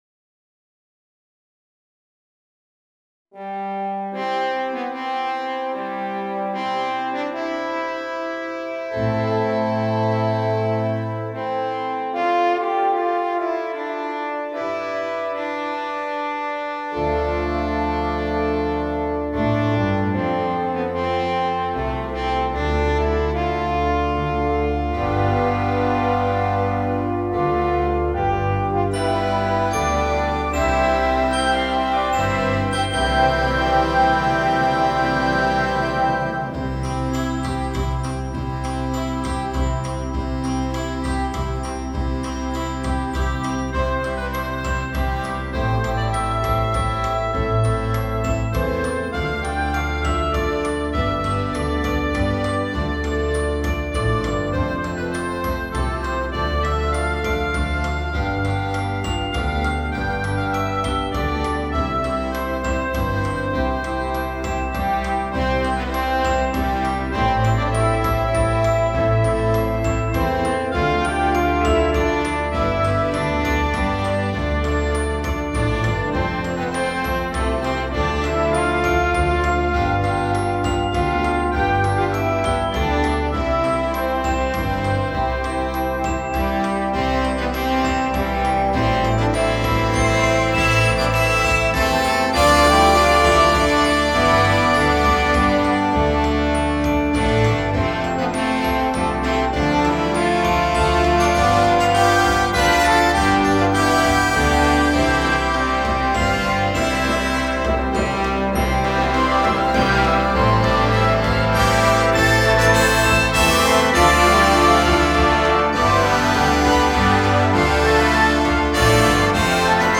It is written in a comfortable range for the French Horn.